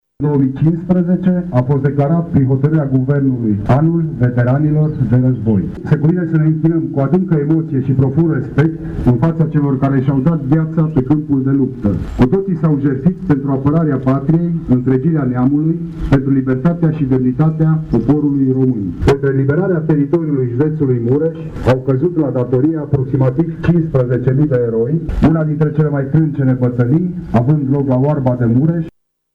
Astăzi, cu prilejul Zilei Veteranilor de Război, la Cimitirul Eroilor a avut loc o ceremonie de comemorare a eroilor români căzuți pe câmpurile de luptă.
Prefectul de Mureș, Lucian Goga, a subliniat că 2015 a fost declarat, prin Hotărâre de Guvern, Anul Veteranilor de Război: